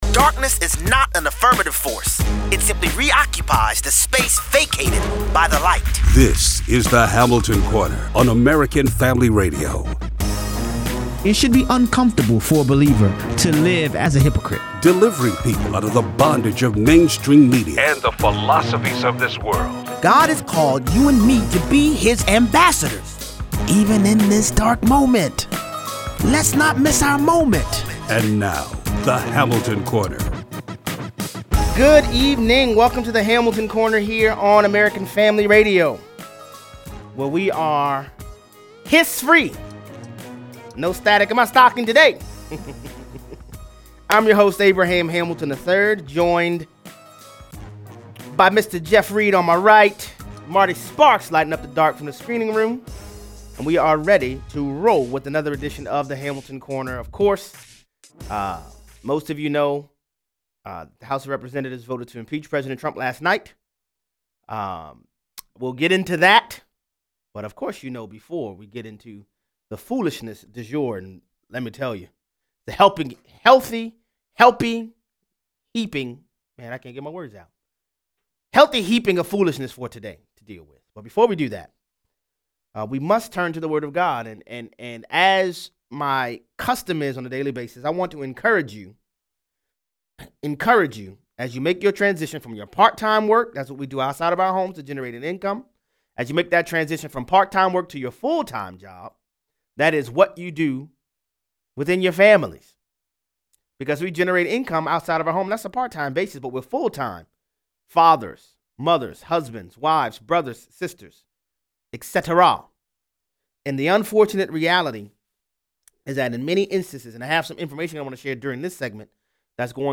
What happens in your house is far more important than what happens in the White House. 18:00 - 35:00. The House of Representatives vote to impeach President Trump in a strict party line vote… but Nancy Pelosi doesn’t want to transmit the Articles of Impeachment to the Senate. 38:00 - 54:30. Bipartisan regressivism on display again… as the nation fixates on impeachment Congress passes a $1.4 trillion spending bill. Callers weigh in.